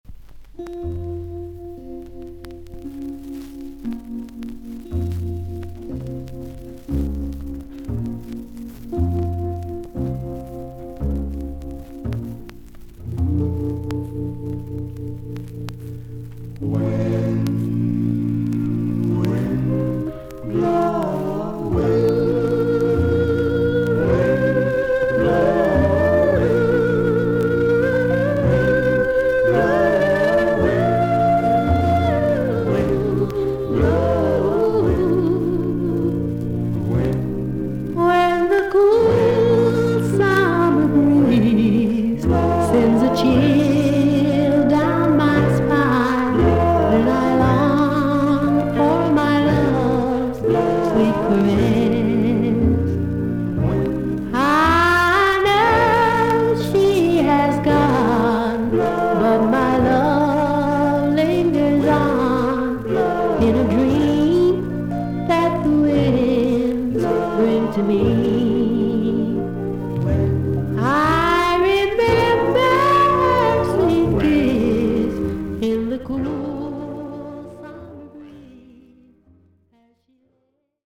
少々軽いパチノイズの箇所あり。少々サーフィス・ノイズあり。クリアな音です。
ドゥーワップ/R&Bグループ。ファルセットにはならないぎりぎりナチュラルな独特のハイテナー・ヴォイス。